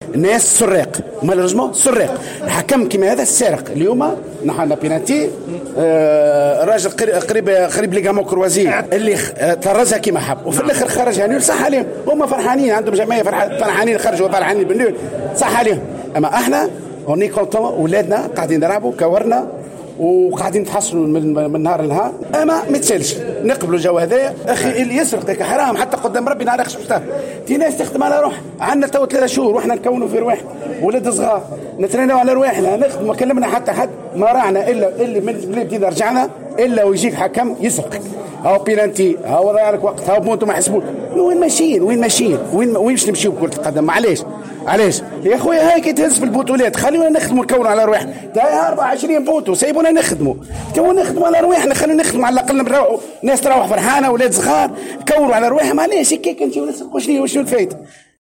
في تصريح للجوهرة اف أم إثر المقابلة